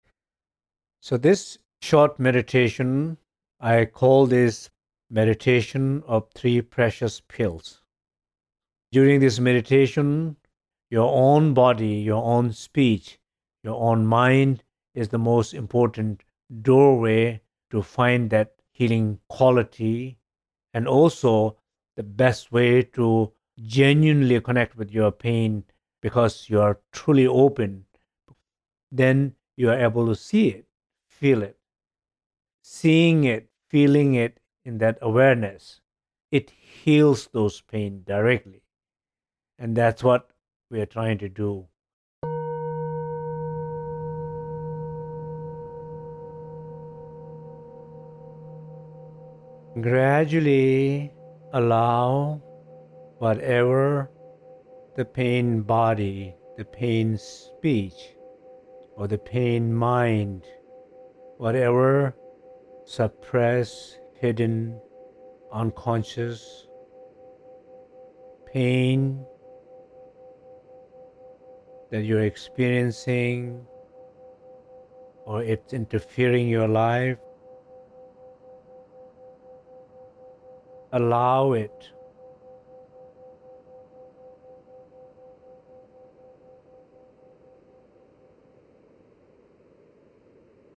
Soucitná a účinná meditace sloužící k léčbě emočních bolestí.